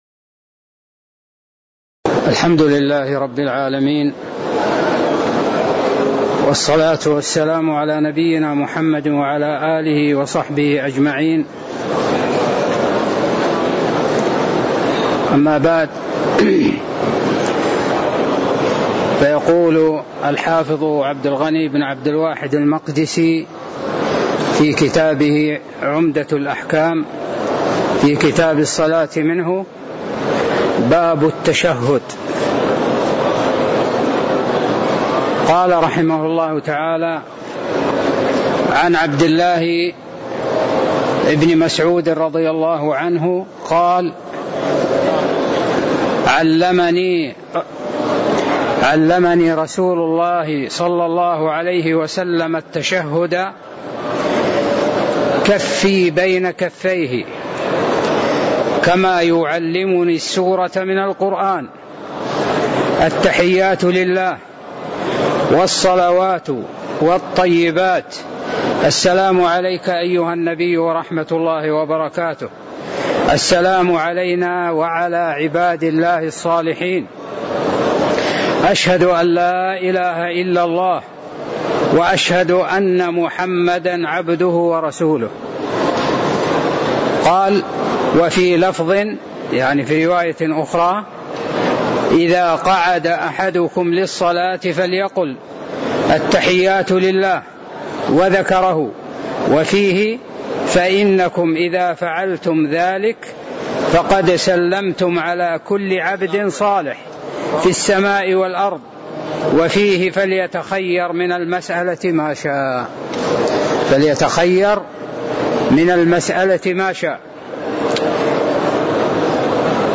تاريخ النشر ١٠ صفر ١٤٣٦ هـ المكان: المسجد النبوي الشيخ